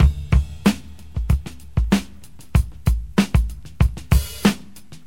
• 95 Bpm Drum Beat D Key.wav
Free drum beat - kick tuned to the D note. Loudest frequency: 912Hz
95-bpm-drum-beat-d-key-LEv.wav